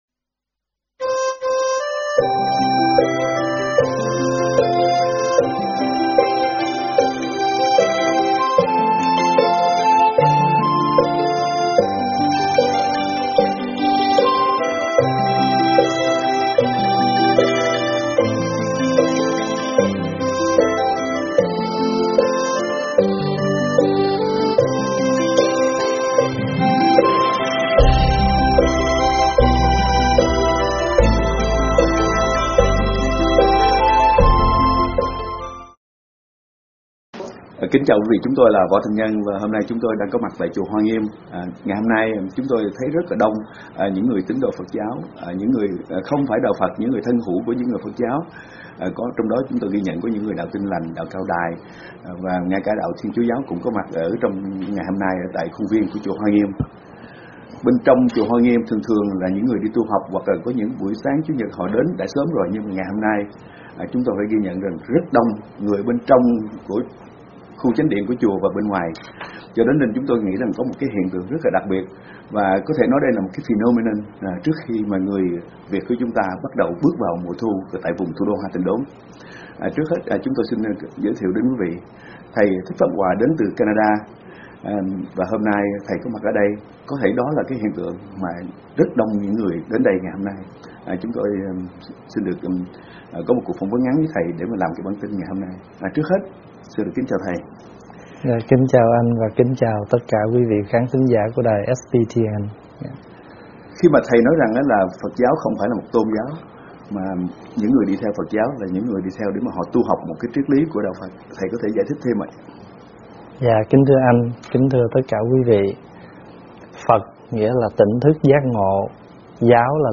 Thuyết pháp Đời Rất Cần Tu Sĩ